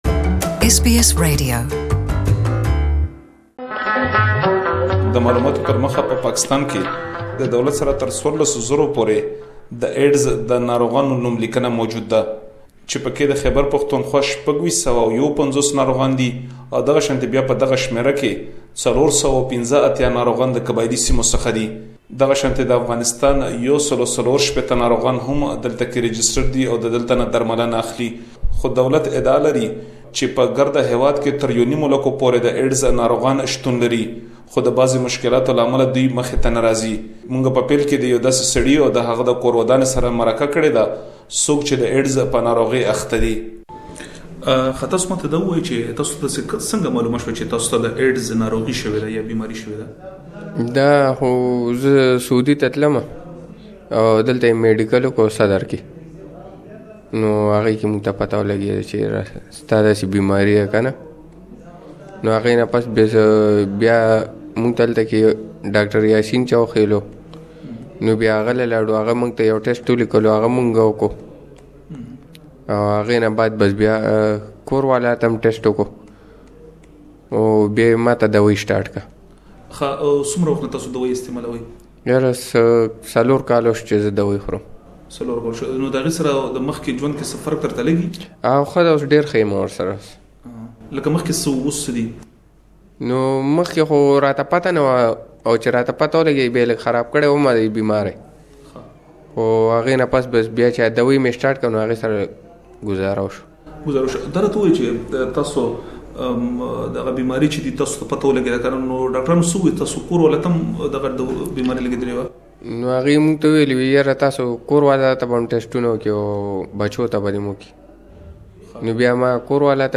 Please listen to the full report in Pashto language.